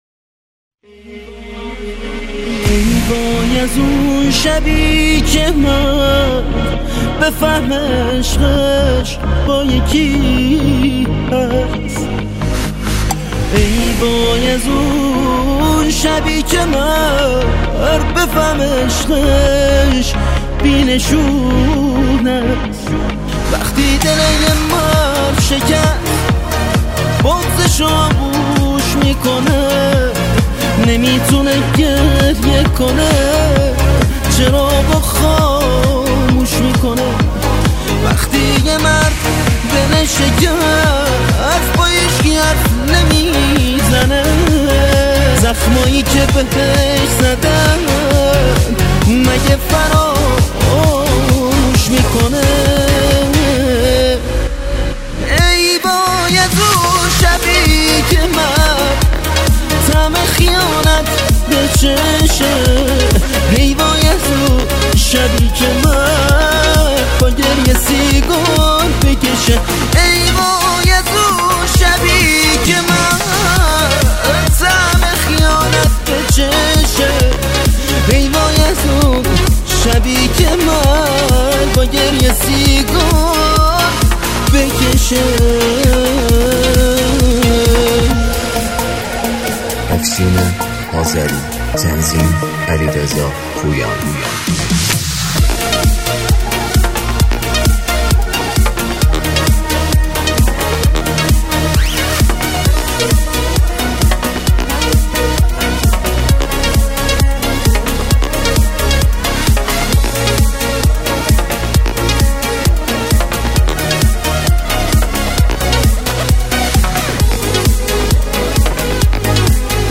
باغلاما